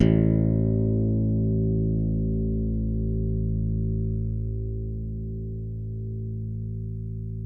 WARW.FING A1.wav